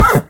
mob / horse / hit2.ogg